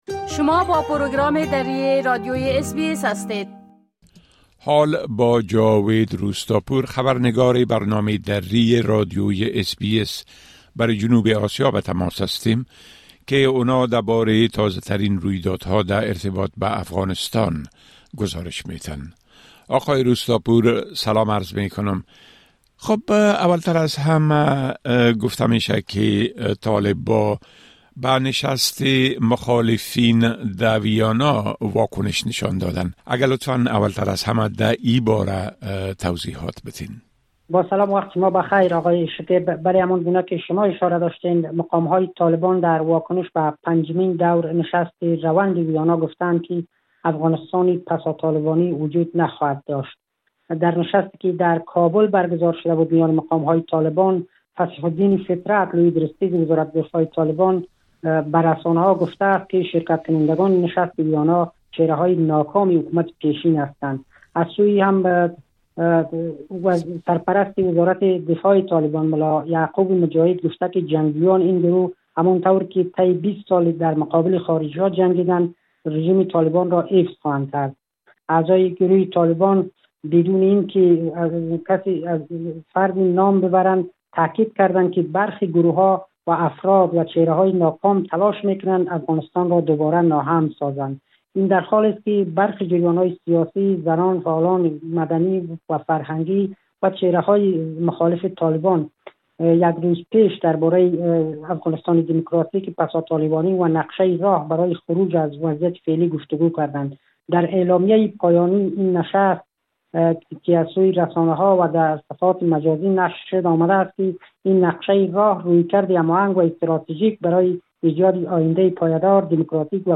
خبرنگار ما برای جنوب آسیا: مقامات طالبان به نشست مخالفين در ويانا واكنش نشان داده اند